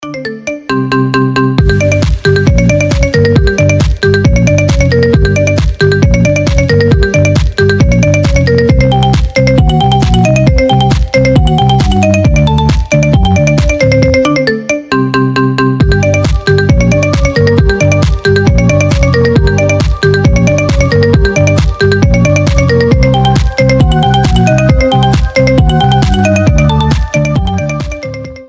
Маримба